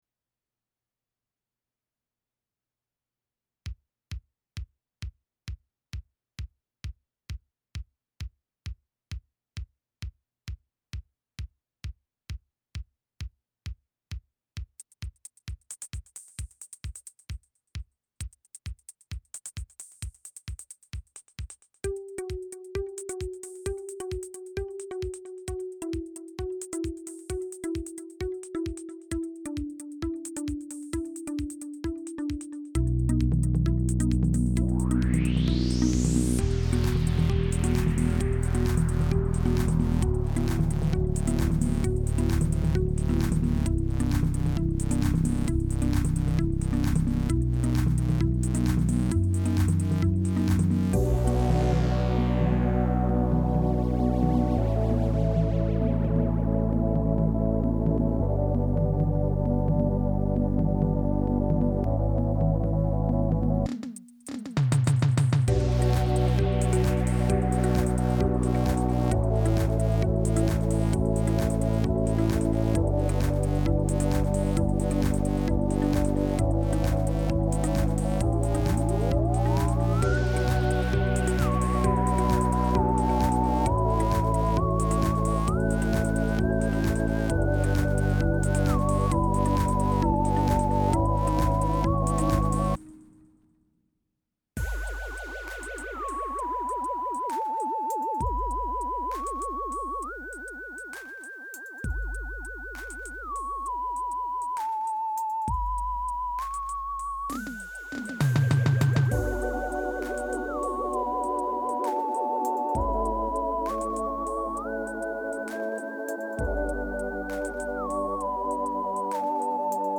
DOWNLOAD ACCOMPANIMENT TRACK